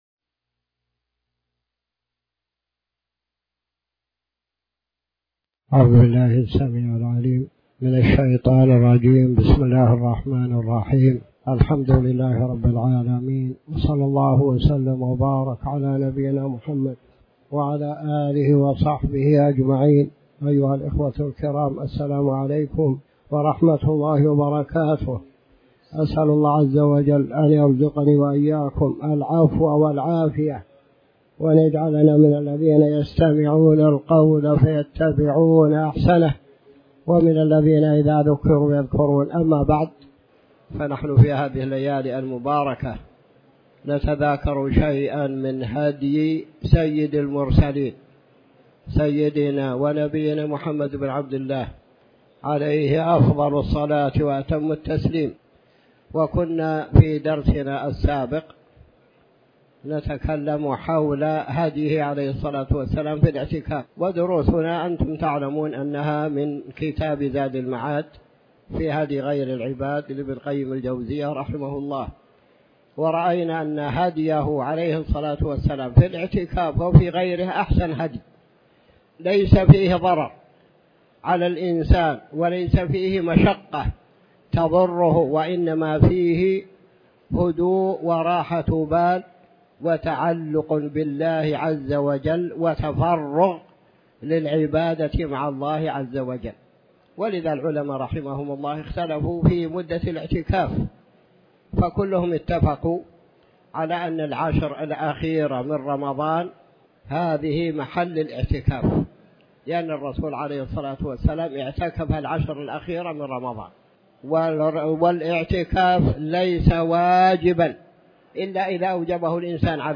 تاريخ النشر ٢١ محرم ١٤٤٠ هـ المكان: المسجد الحرام الشيخ